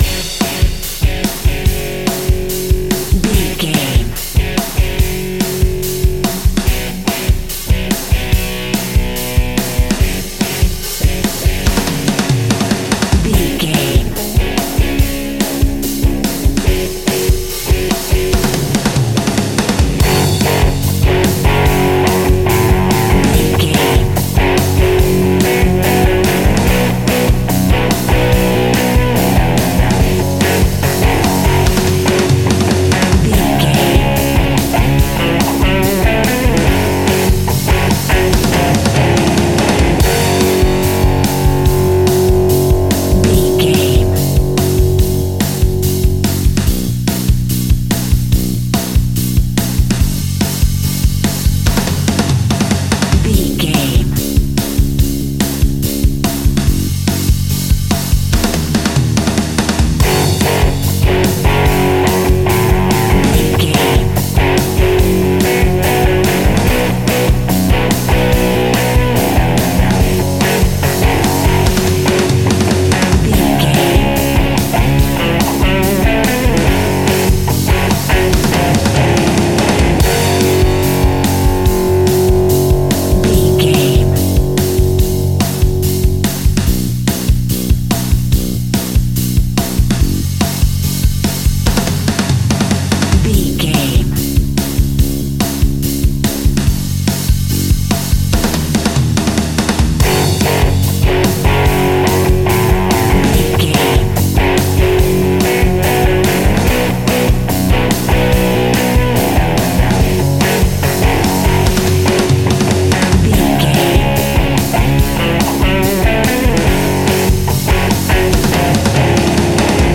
Epic / Action
Fast paced
Ionian/Major
F#
hard rock
blues rock
rock instrumentals
Rock Bass
heavy drums
distorted guitars
hammond organ